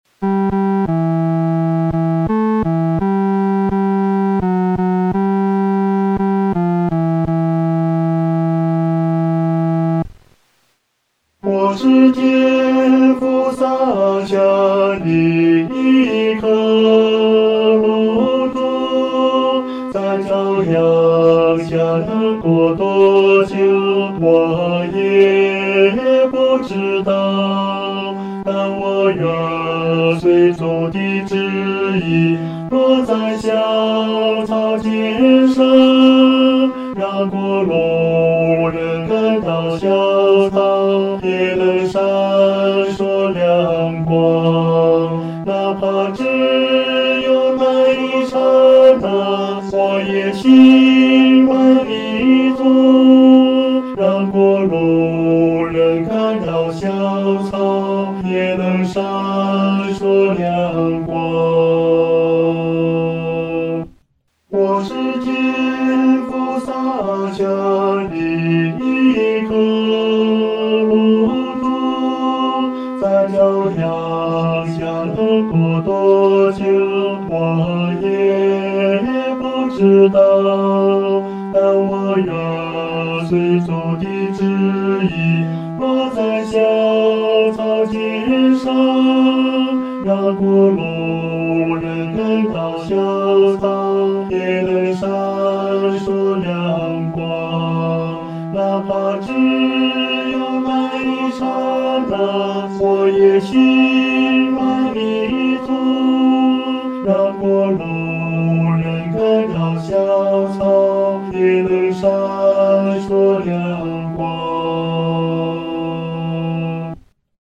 合唱
男高
曲调欢快、活泼，好唱易记，既适合成人唱，又适合年轻人唱，还特别适合儿童表演唱。